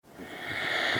HippoSnores-001.wav